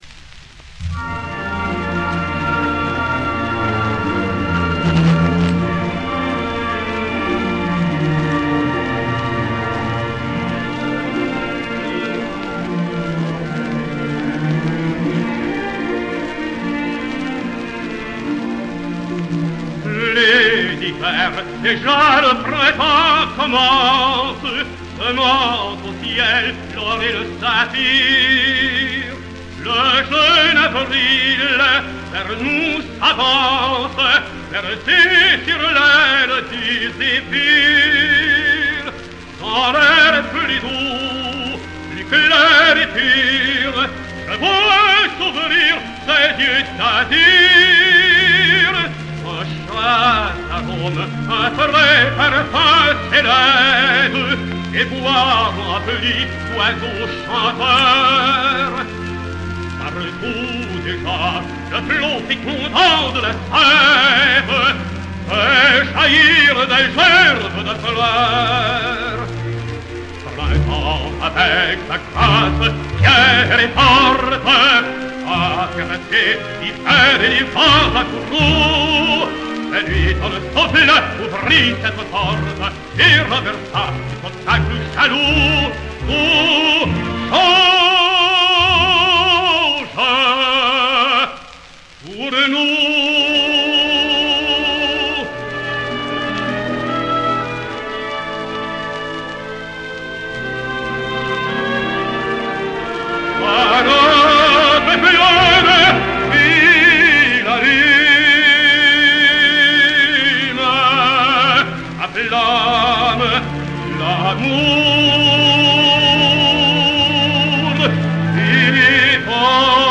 Charles Rousselière sings Die Walküre: